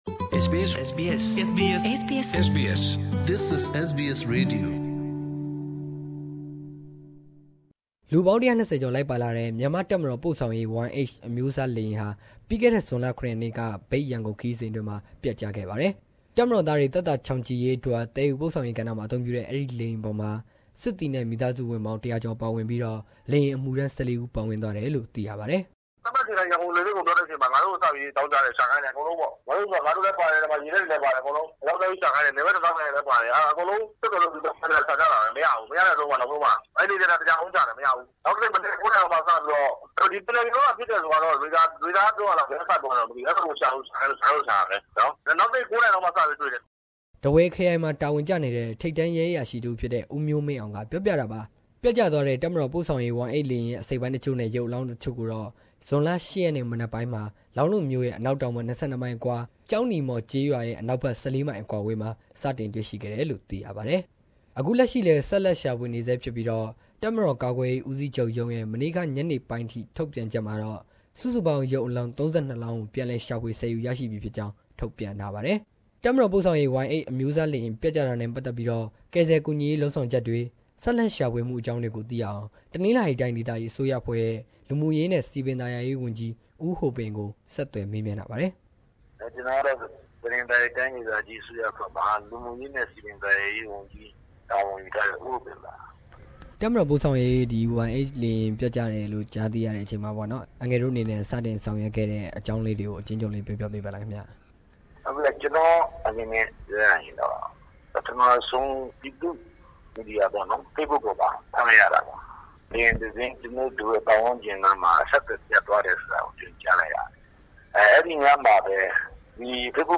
Report on Myanmar Air Force Shaanxi Y-8 crash